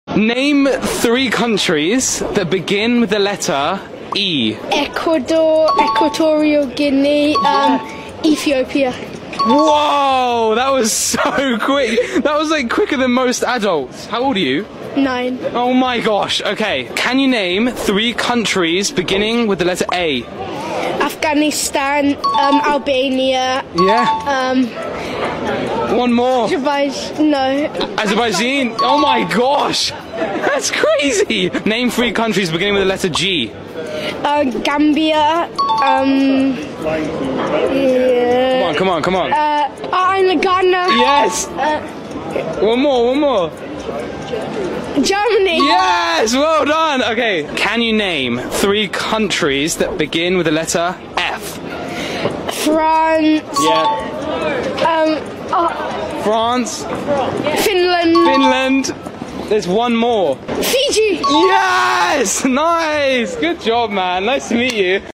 street interview sound effects free download